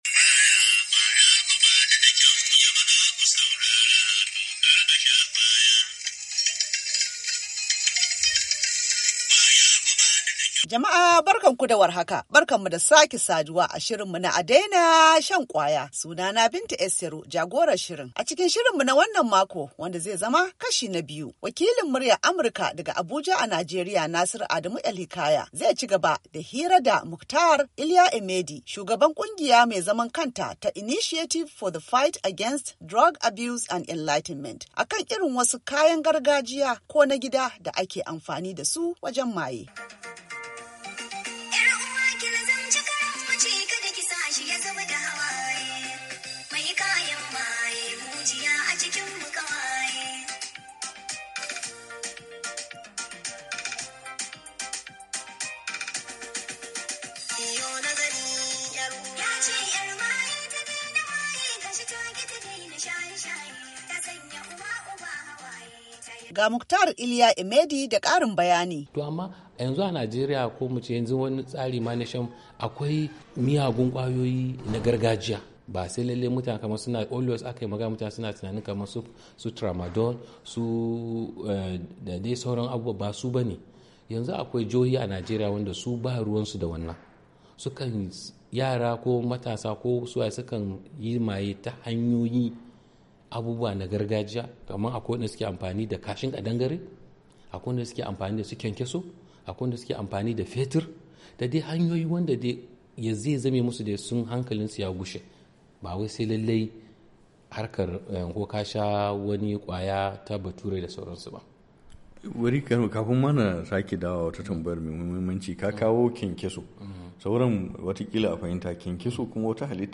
A Daina Shan Kwaya: Hira Da Shugaban Wata Kungiya Mai Fadakar Da Jama’a Akan Illolin Shaye-Shayen Miyagun Kwayoyi; Kashi Na Biyu